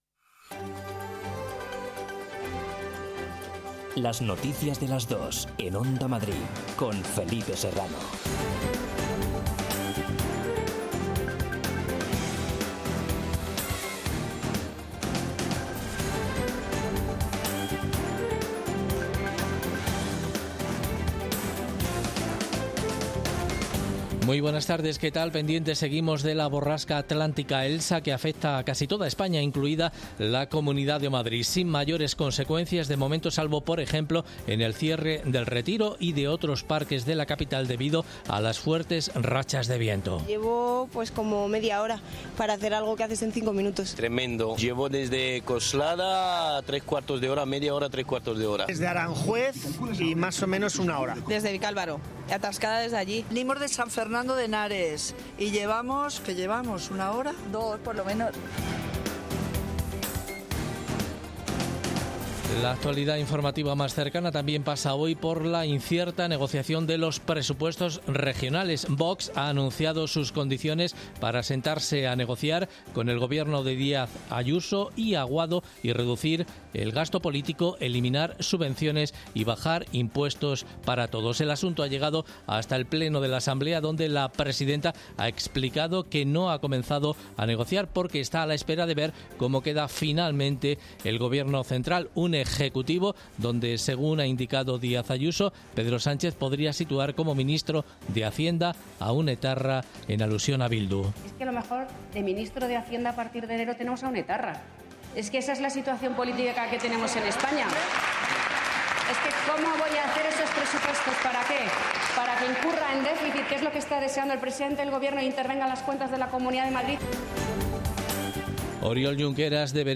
en España y en el Mundo. 60 minutos de información diaria con los protagonistas del día, y conexiones en directo en los puntos que a esa hora son noticia